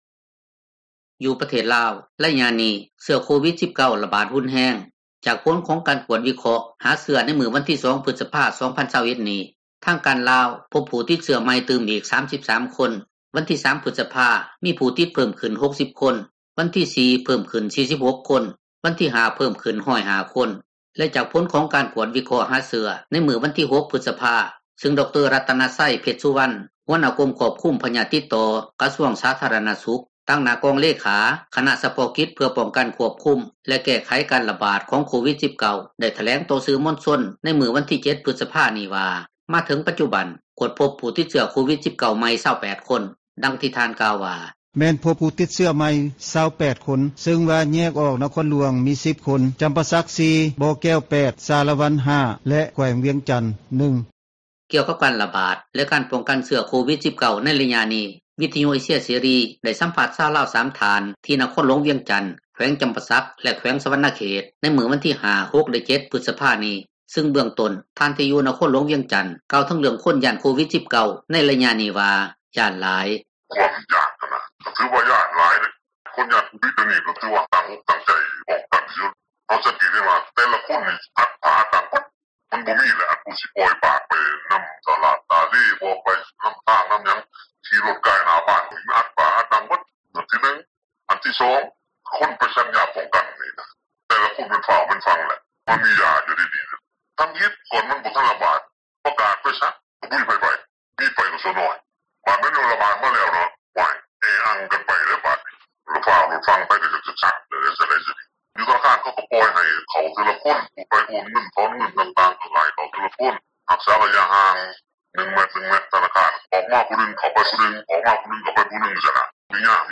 ກ່ຽວກັບການຣະບາດ ແລະການປ້ອງກັນເຊື້ອໂຄວິດ-19 ໃນໄລຍະນີ້ ວິທຍຸເອເຊັຍເສຣີ ໄດ້ສໍາພາດຊາວລາວ 3 ທ່ານ ທີ່ນະຄອນ ຫລວງວຽງຈັນ, ແຂວງຈໍາປາສັກ ແລະ ແຂວງສວັນນະເຂດ ໃນມື້ວັນທີ 5, 6, 7 ພຶສພາ ນີ້, ຊຶ່ງເບື້ອງຕົ້ນ ຜູ້ທີ່ຢູ່ນະຄອນຫຼວງ ວຽງຈັນ ກ່າວເຖິງເຣື່ອງຄົນຢ້ານ ໂຄວິດ-19 ໃນໄລຍະນີ້ວ່າ ຢ້ານຫລາຍ: